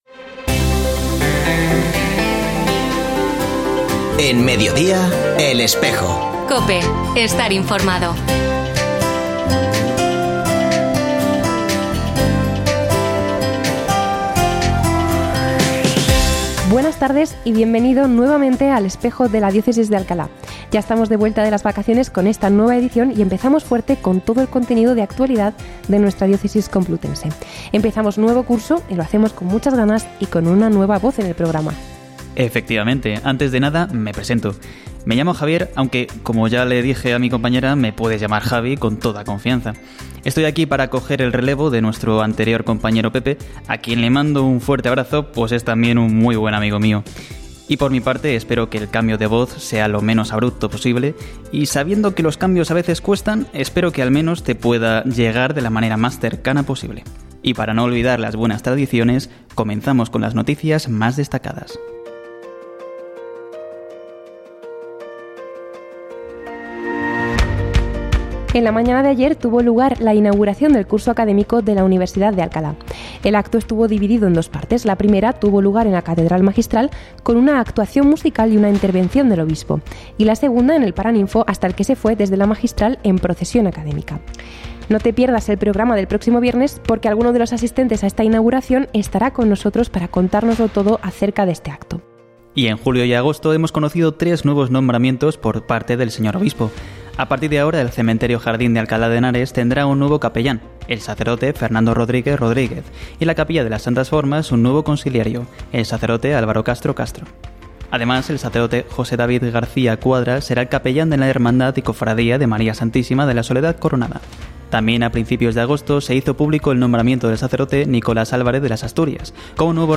Escucha otras entrevistas de El Espejo de la Diócesis de Alcalá
Como cada viernes, se ha vuelto a emitir hoy, 6 de septiembre de 2024, en radio COPE. Este espacio de información religiosa de nuestra diócesis puede escucharse en la frecuencia 92.0 FM, todos los viernes de 13.33 a 14 horas.